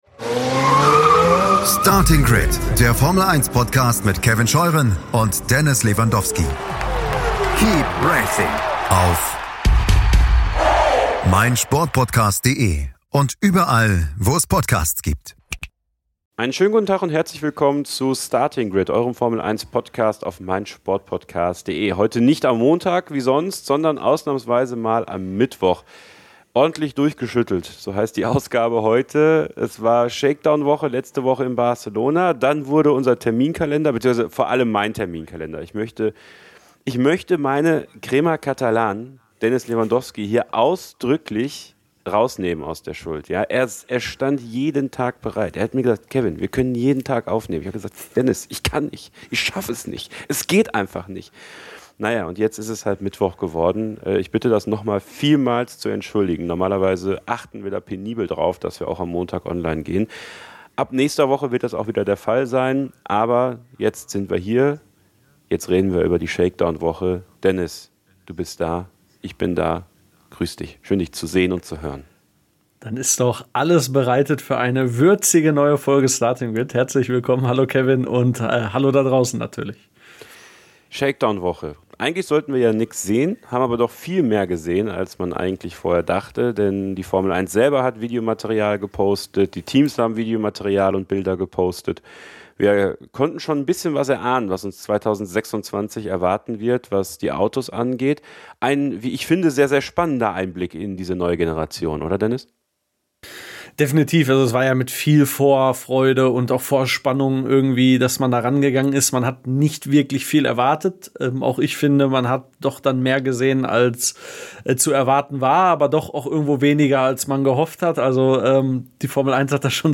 RTL-Moderator Florian König hat sich sehr viel Zeit für ein ausführliches Interview mit genommen und präsentiert sich reflektiert und ehrlich.